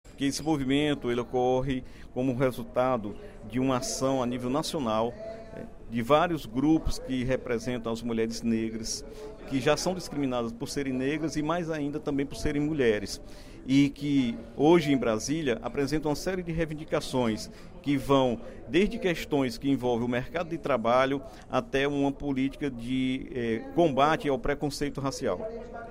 O deputado Dr. Santana (PT) fez pronunciamento, nesta quarta-feira (18/11), durante o primeiro expediente, para destacar a realização da Marcha das Mulheres Negras, hoje, em Brasília. A manifestação tem como objetivo denunciar o racismo e ocorre dentro da Semana da Consciência Negra.